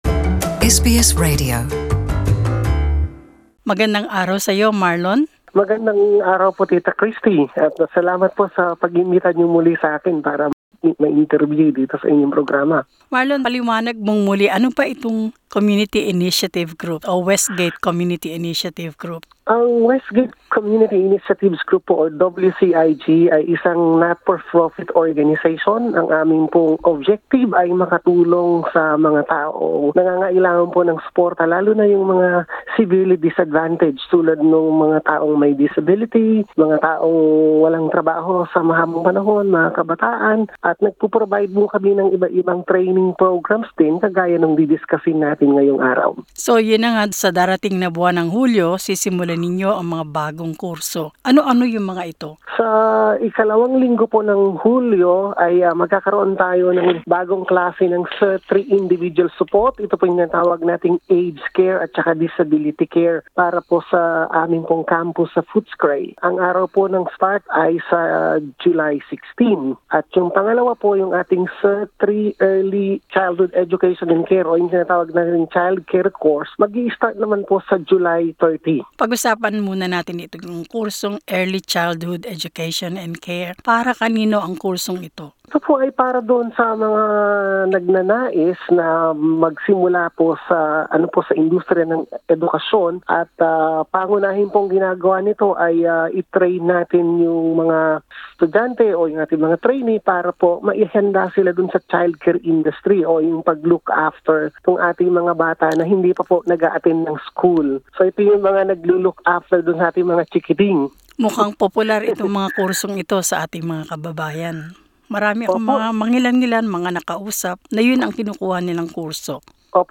Layunin ng mga kursong tinatalakay sa panayam na ito ang makatulong sa mga taong nagnanais magtrabaho sa industriya ng childcare, aged care at disability care.